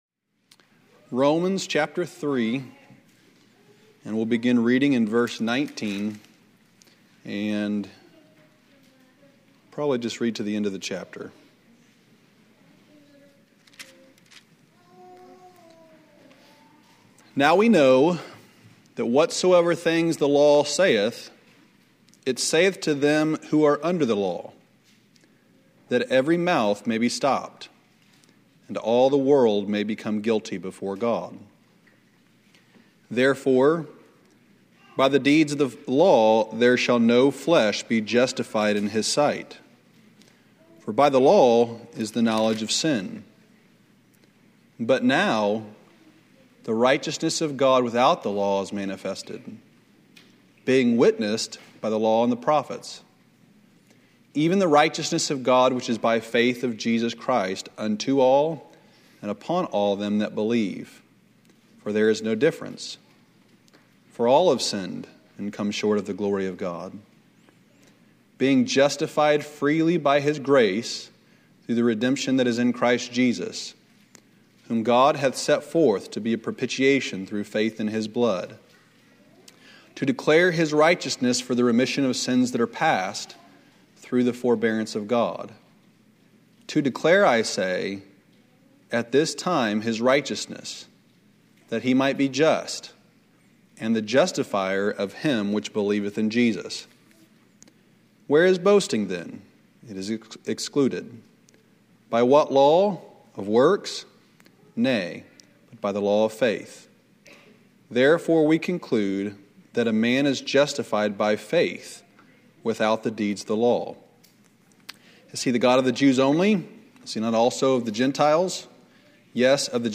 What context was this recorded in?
" Sunday Morning Messages " Sermons from our Sunday morning worship services.